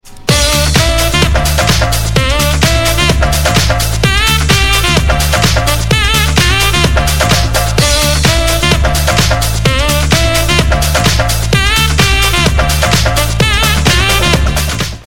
• Качество: 320, Stereo
зажигательные
dance
Electronic
без слов
Саксофон
house